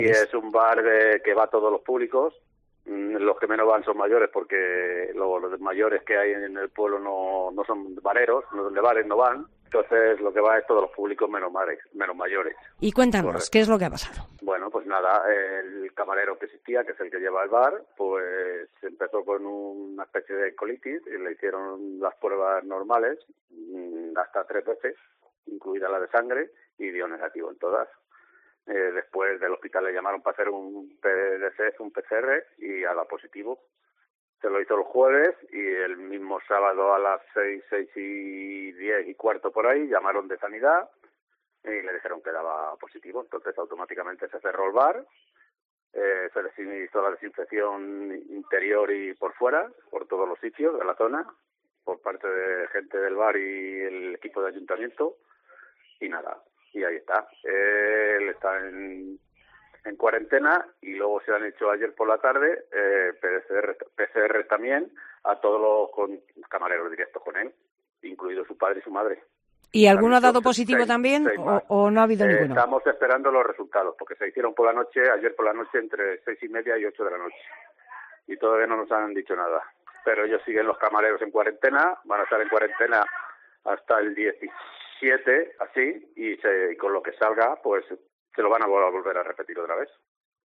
Entrevista al alcalde de Santa Ana de Pusa